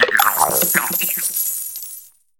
Cri de Mordudor dans sa forme Coffre dans Pokémon HOME.
Cri_0999_Coffre_HOME.ogg